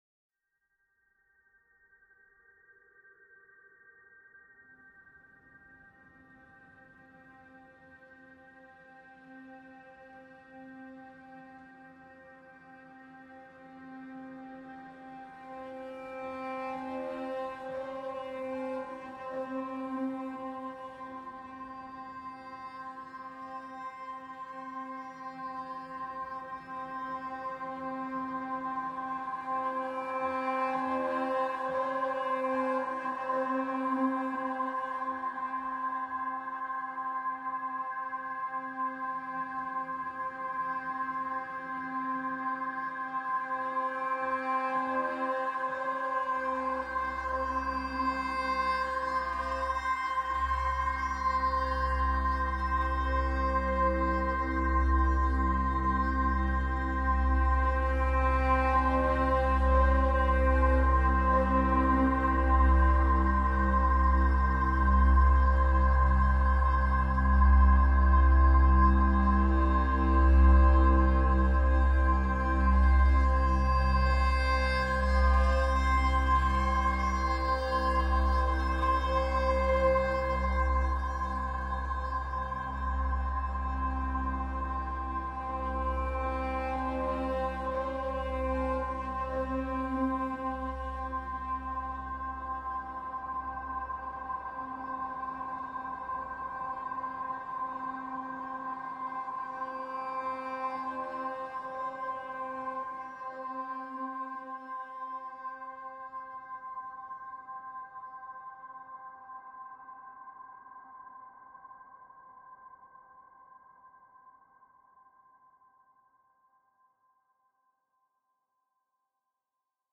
only pads